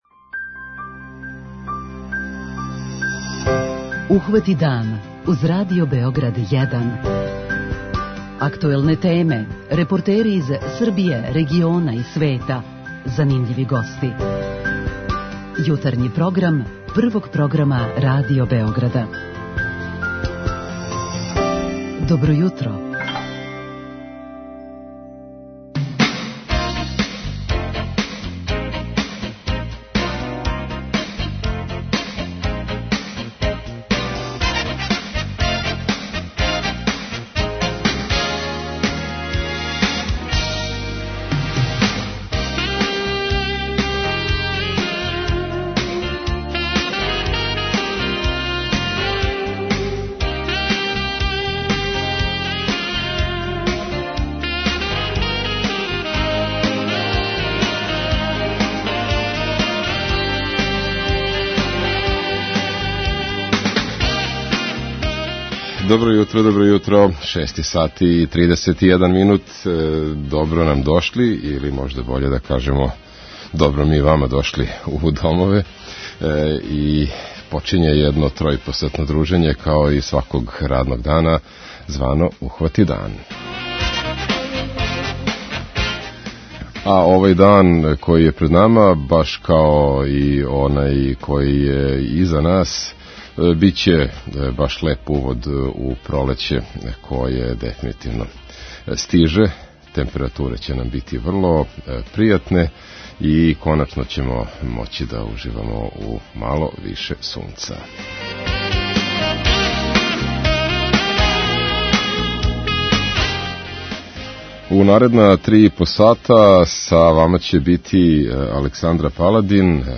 Група аутора Јутарњи програм Радио Београда 1!
У емисији ћемо чути и прилог о избору за председника Фудбалског савеза Србије, укључујући и изјаве главних кандидата - Драгана Џајића и Немање Видића.